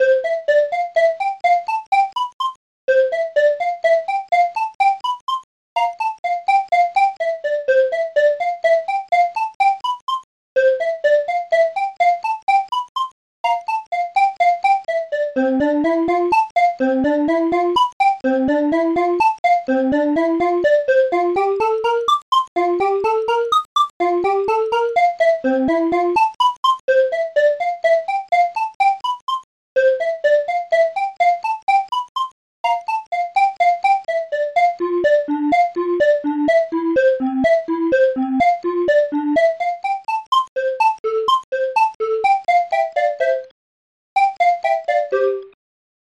ST-01:flute
ST-01:tshaker